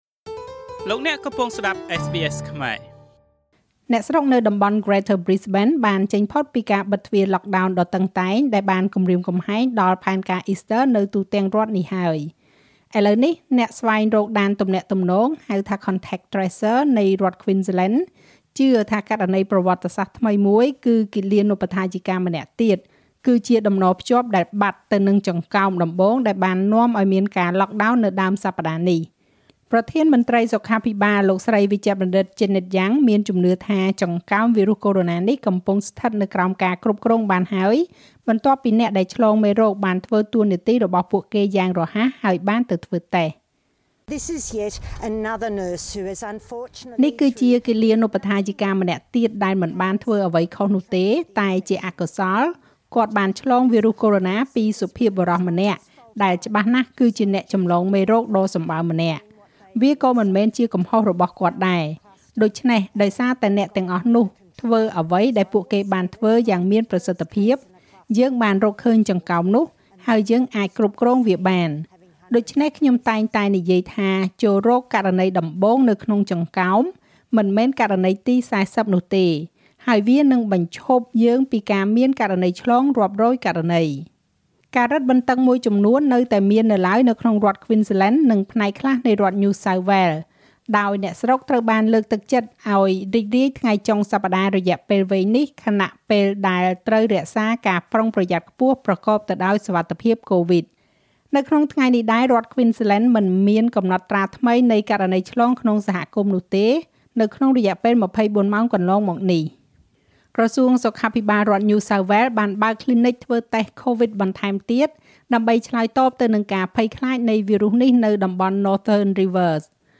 នាទីព័ត៌មានរបស់SBSខ្មែរ សម្រាប់ថ្ងៃសុក្រ ទី២ ខែមេសា ឆ្នាំ២០២១។